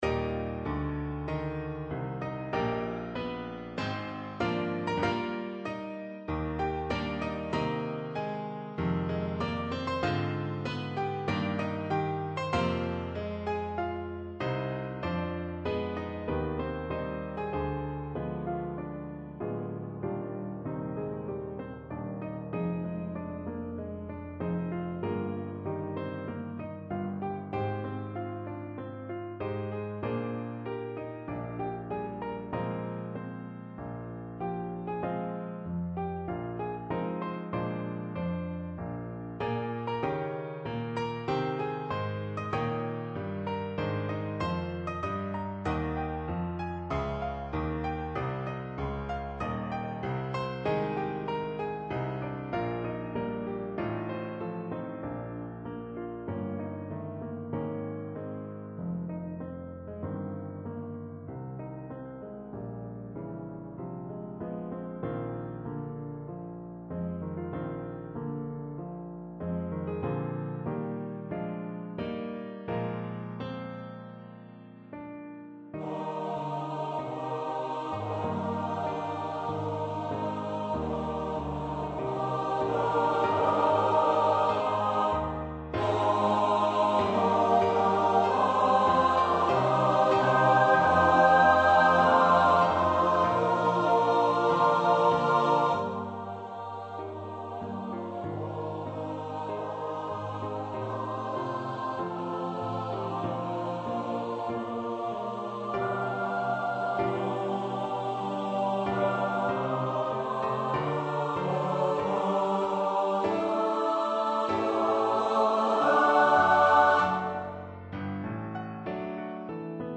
The audio sample is of the choir and piano version.